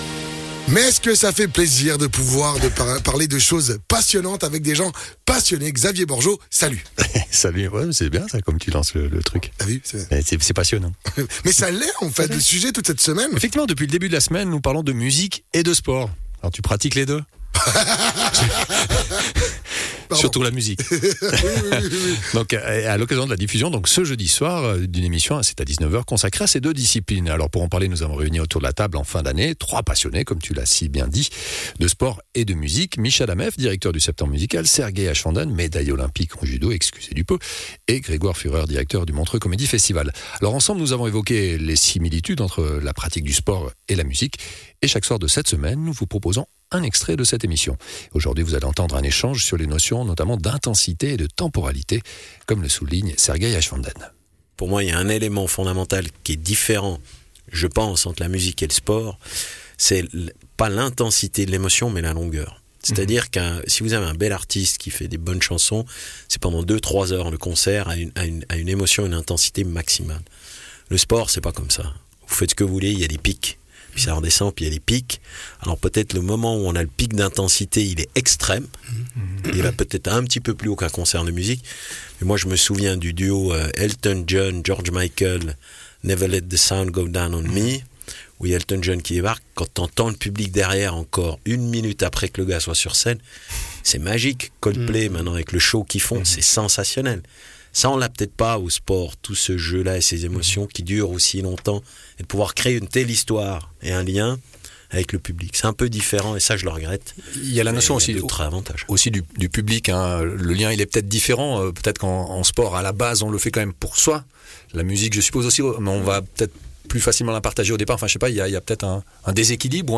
Sergei Aschwanden, Médaillé Olympique en judo